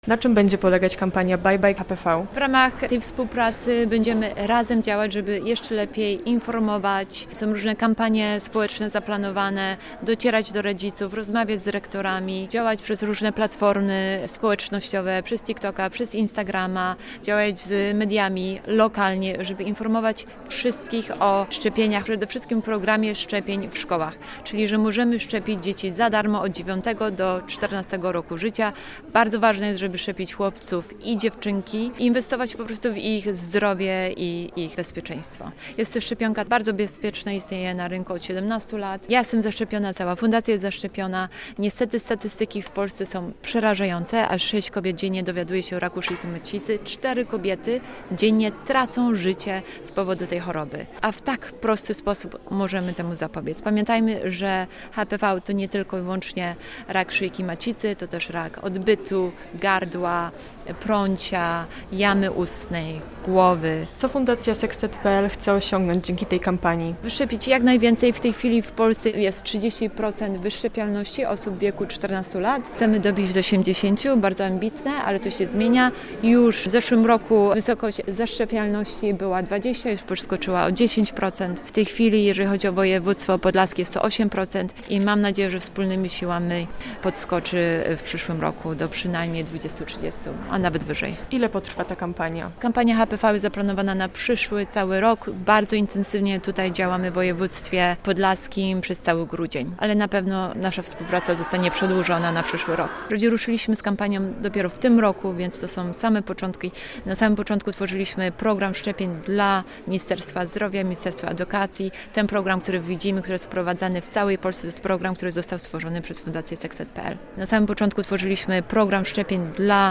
Radio Białystok | Gość | Anja Rubik - ambasadorka akcji szczepień przeciwko HPV